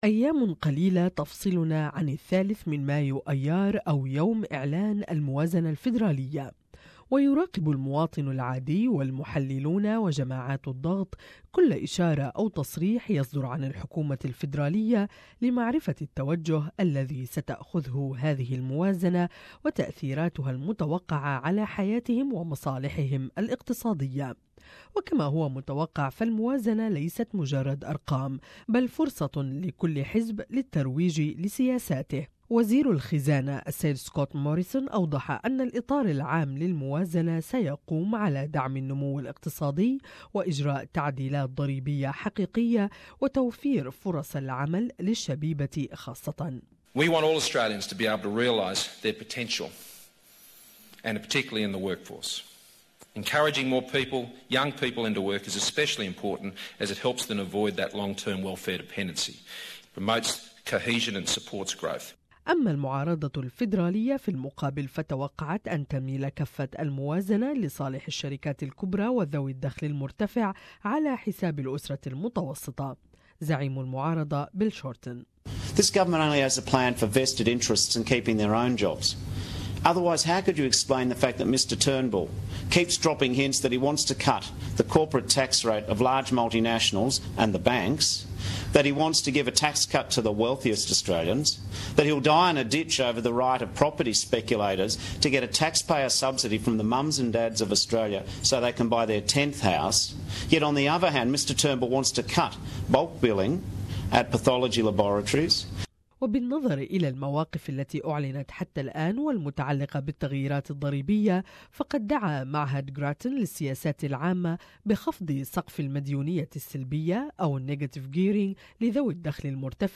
تقرير اخباري